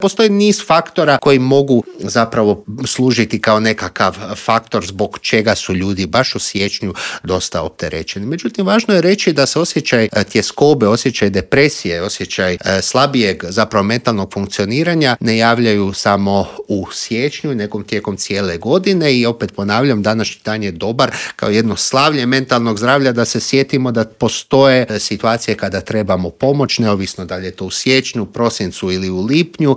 ZAGREB - Najdepresivniji je dan u godini pa smo u studiju Media servisa razgovarali o mentalnom zdravlju.